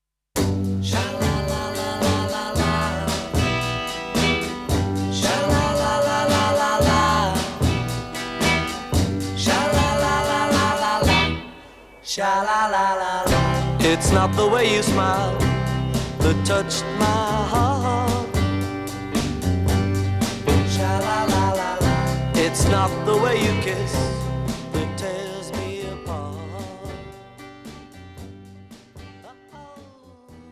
zpěv, kytara
basová kytara
sólová kytara
bicí
klávesy